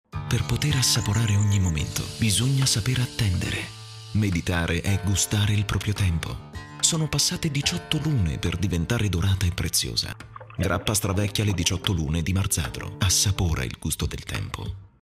品牌广告【时尚感性】